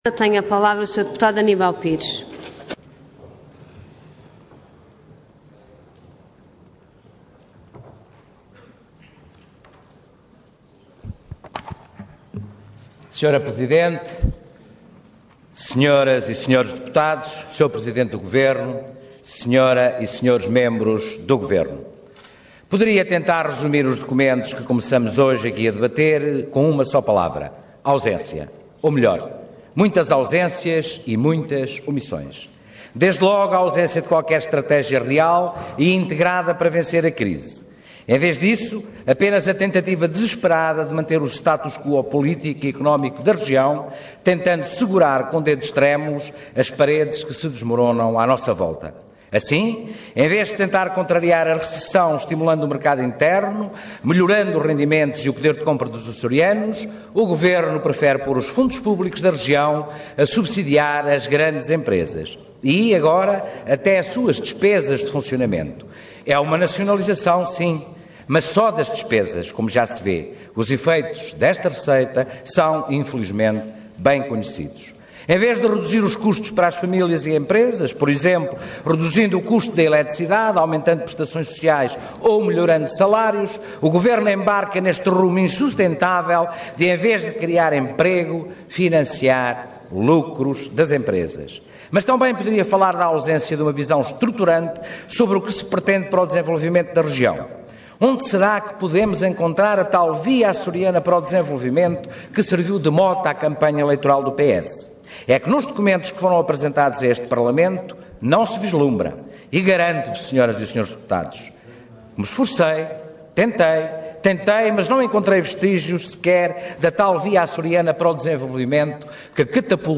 Intervenção Intervenção de Tribuna Orador Aníbal Pires Cargo Deputado Entidade PCP